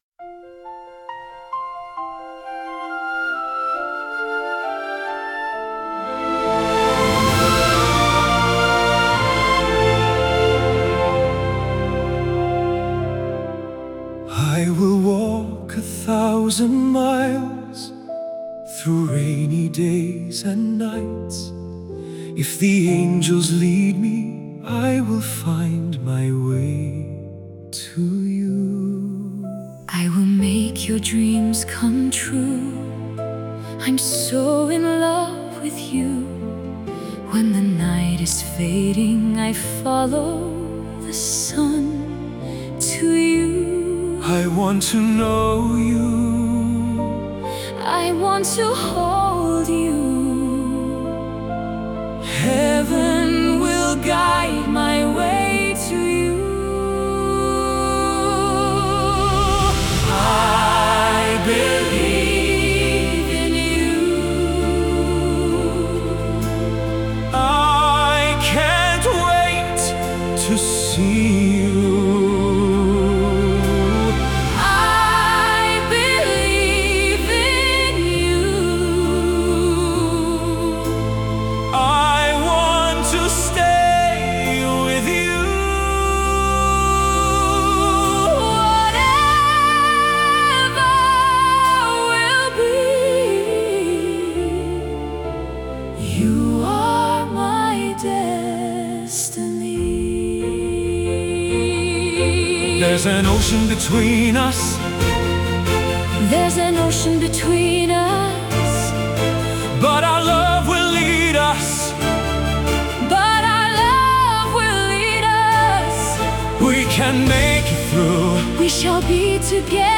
All songs on this page are Demo-Songs by EH Production